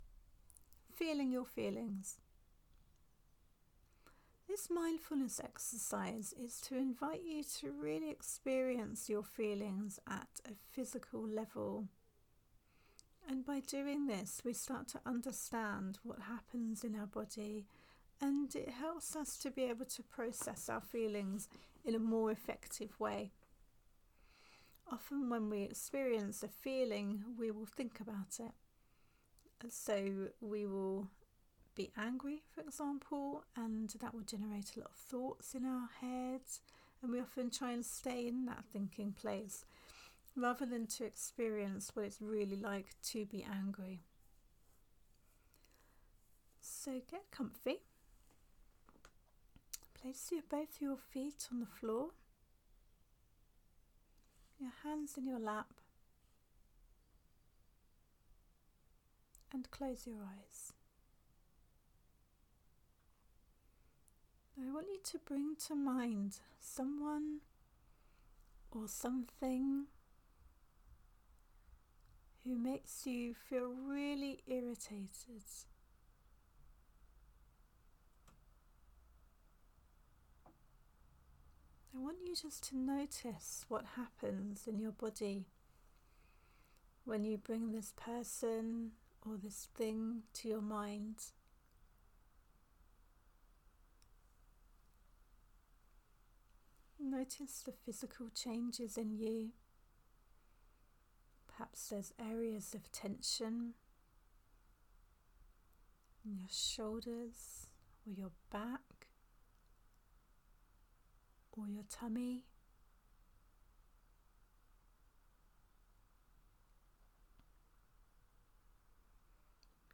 Downloadable Meditation Exercises